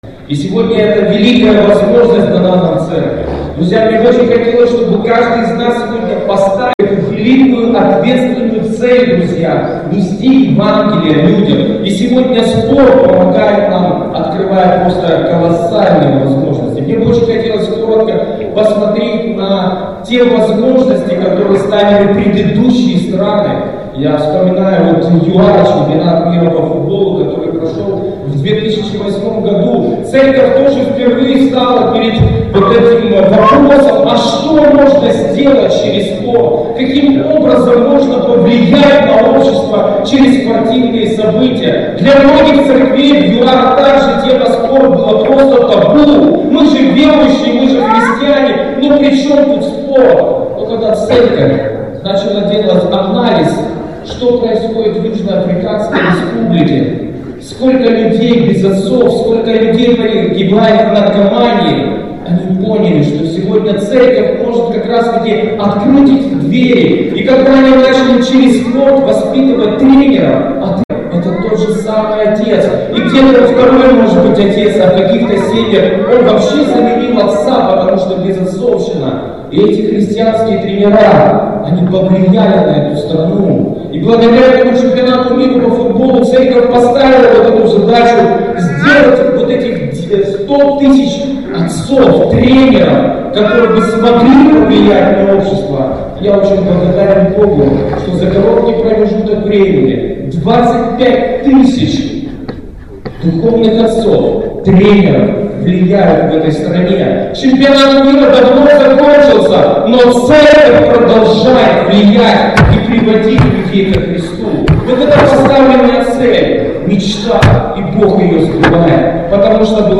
Вступительное слово на форуме "Точка входа - спорт"
Церковь: Христианский центр в Раменском
Книга Библии: Семинар